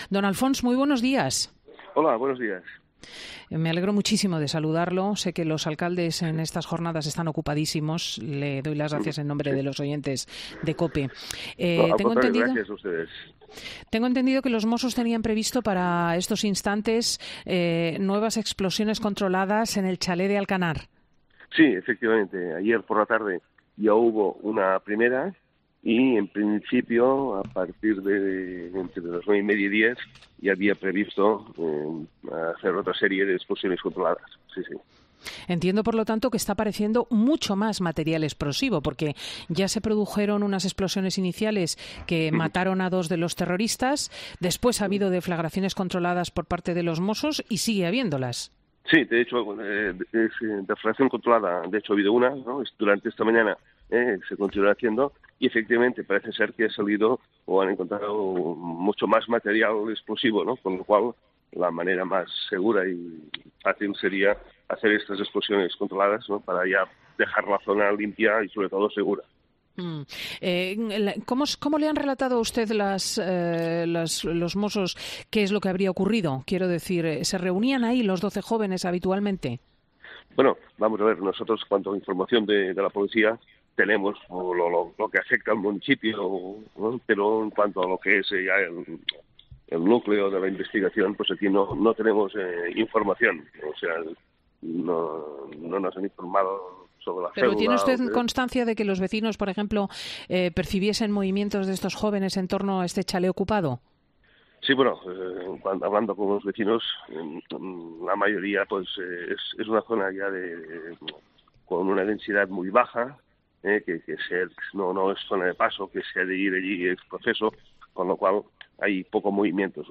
Alfons Monserrat, alcalde de Alcanar